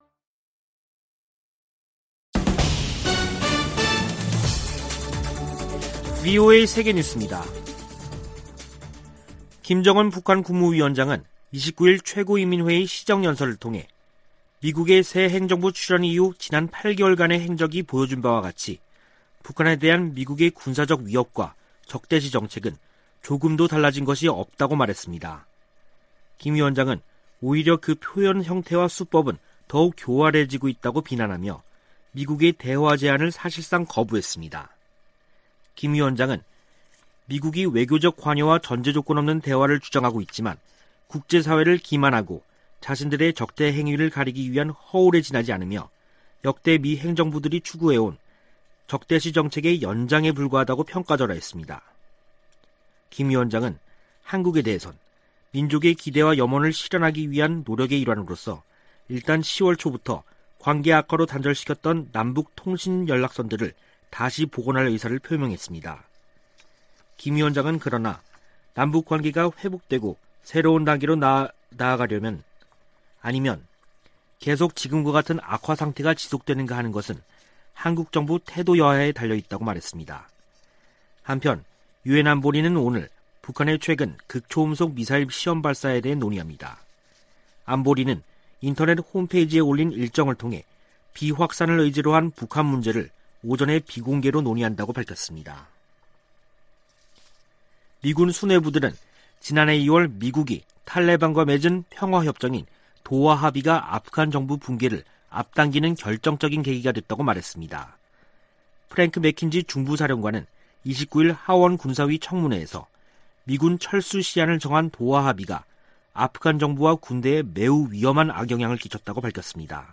VOA 한국어 간판 뉴스 프로그램 '뉴스 투데이', 2021년 9월 30일 3부 방송입니다. 김정은 북한 국무위원장은 조 바이든 행정부에서도 미국의 대북 적대시 정책이 변한 게 없다며 조건 없는 대화 재개를 거부했습니다. 미 국무부는 북한에 적대적 의도가 없다고 거듭 강조했습니다. 보니 젠킨스 미 국무차관은 북한의 최근 미사일 발사들에 대한 추가 정보를 파악하고 있다며, 대북 외교를 계속 추진할 것이라고 밝혔습니다.